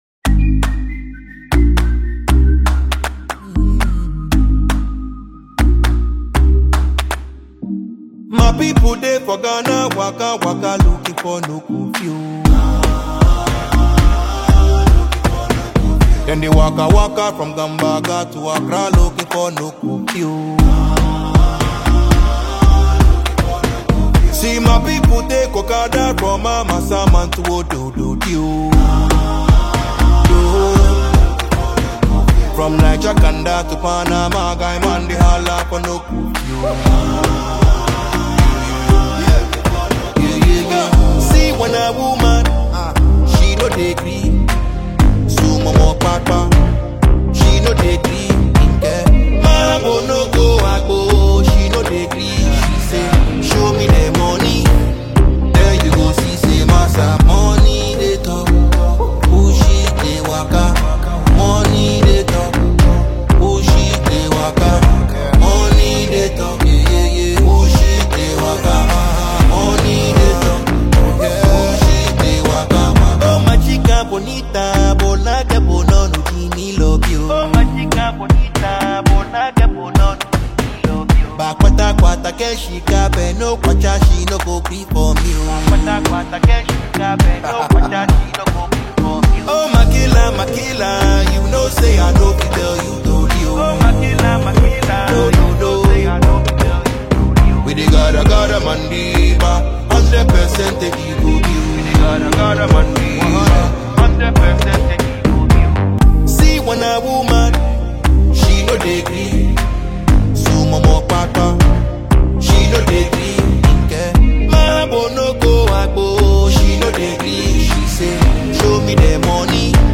• Genre: Afrobeat / Hip-Hop